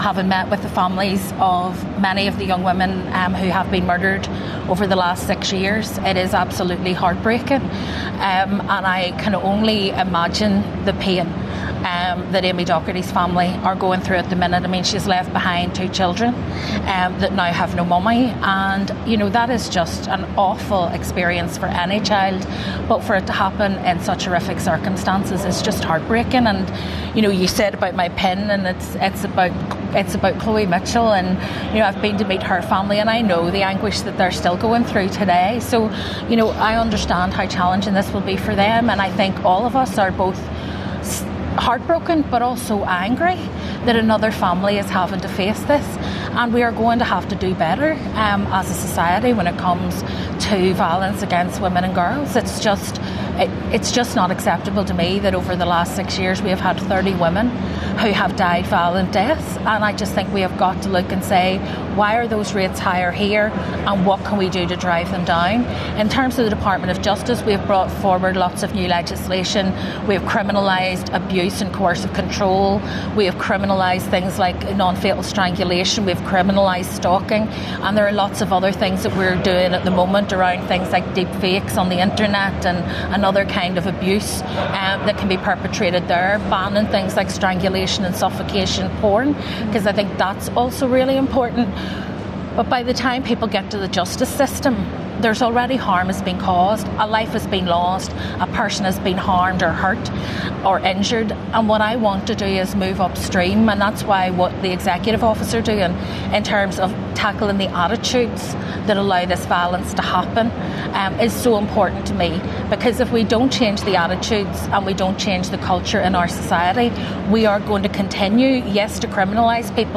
The North’s Justice Minister says attitudes need to change if the scourge of violence against women and girls is to be tackled in Northern Ireland.